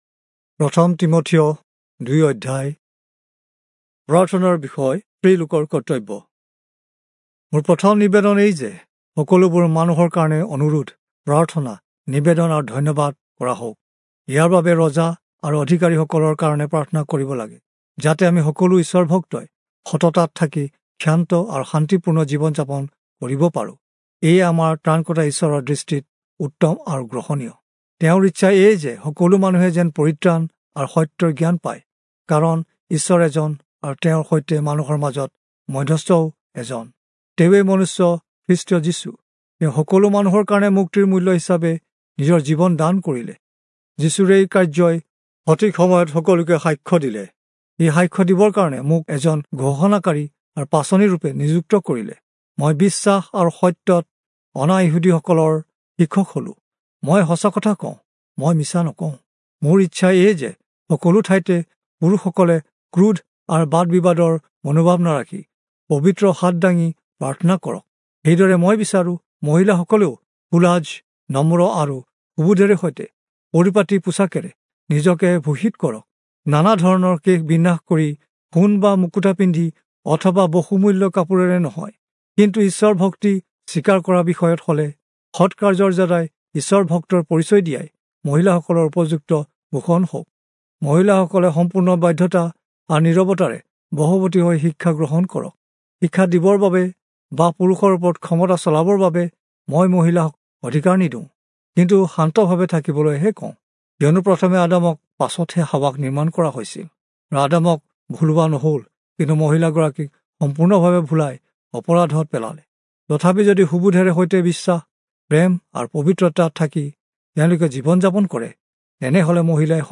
Assamese Audio Bible - 1-Timothy 1 in Kjv bible version